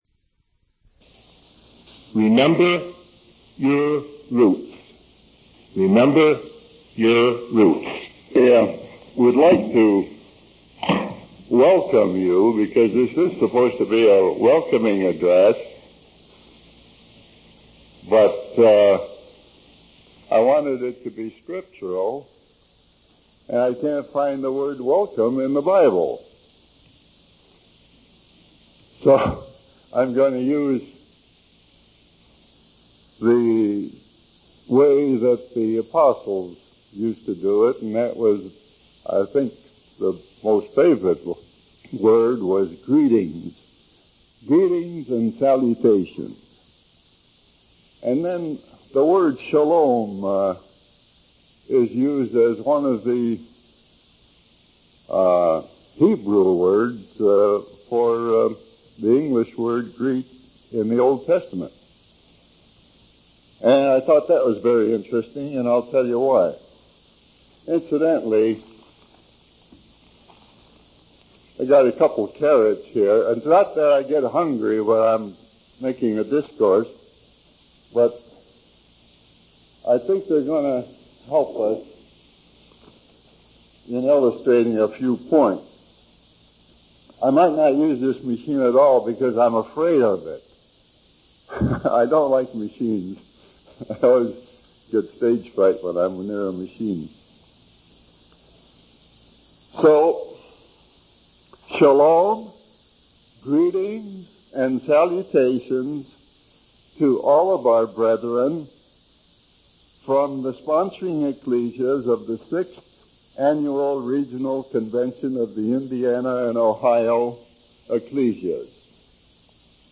From Type: "Discourse"